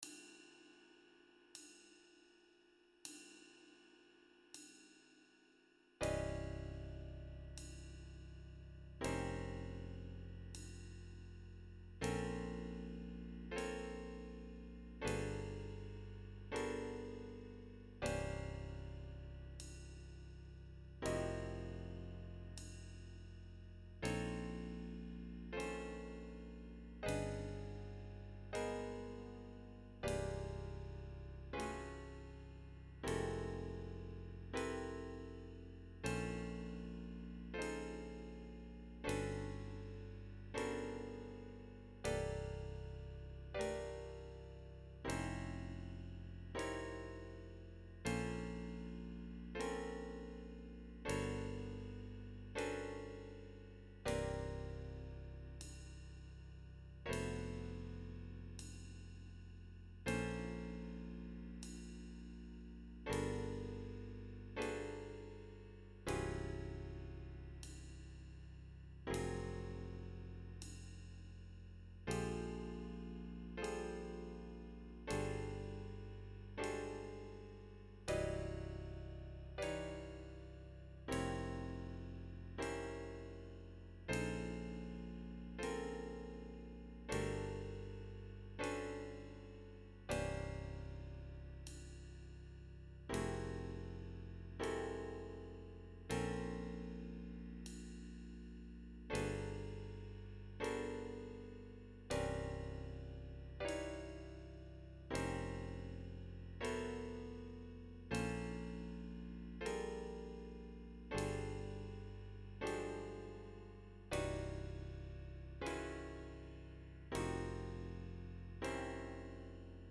MP3 with Bass and Cymbal